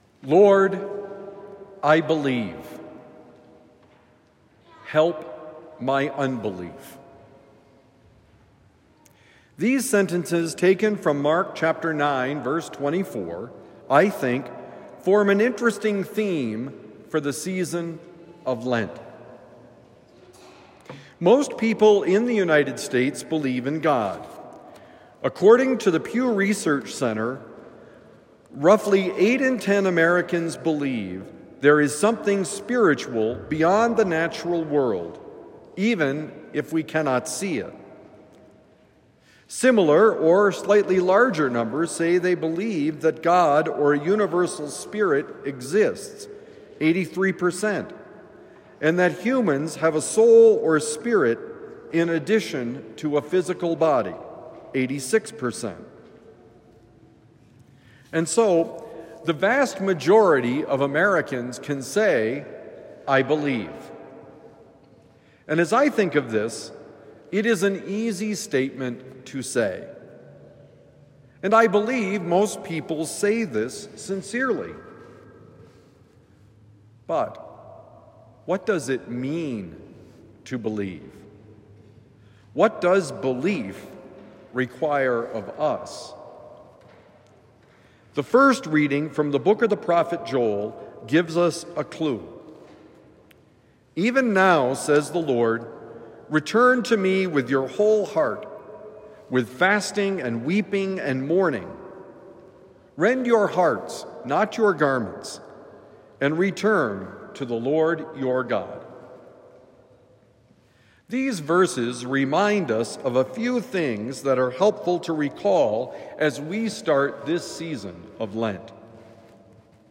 The theme for Lent: Believe: Homily for Wednesday, March 5, 2025